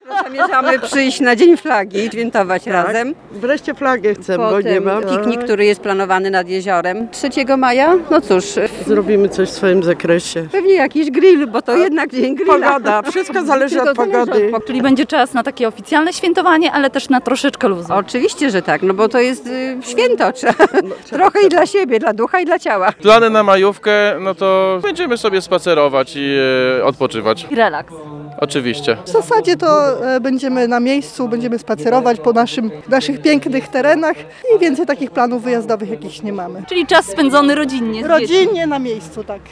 Pytani przez reporterkę Radia 5 zaznaczają, że po udziale w oficjalnych uroczystościach związanych ze Świętem Flagi 2 maja i uchwaleniem Konstytucji 3 maja, na pewno znajdą czas na odpoczynek na łonie natury.